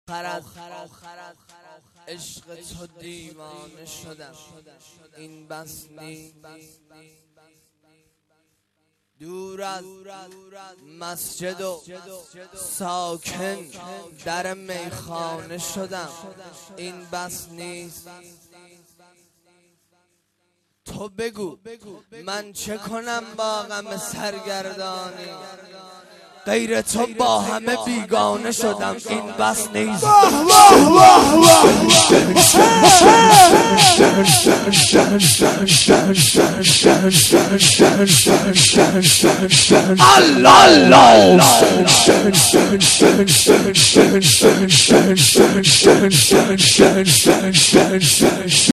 • رجز اجرا شده در محفل زینبیون مشهدالرضا